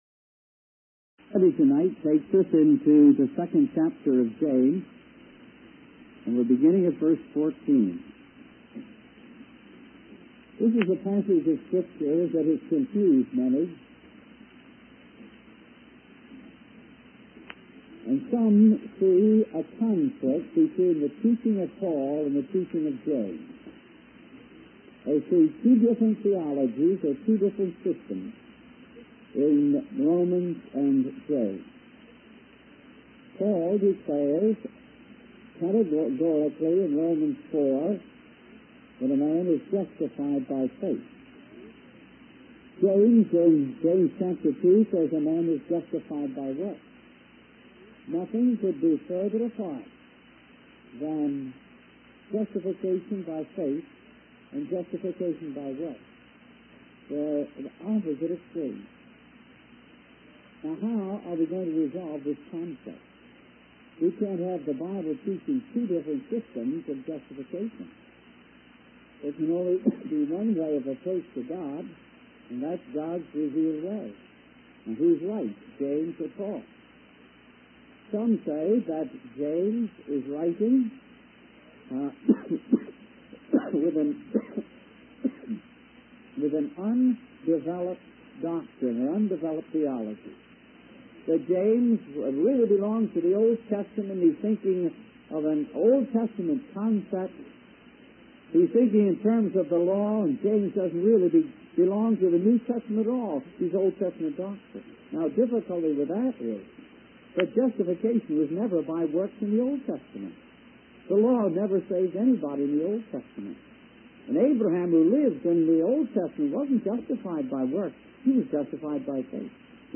In this sermon, the preacher addresses the apparent conflict between the teachings of Paul and James regarding justification by faith and works.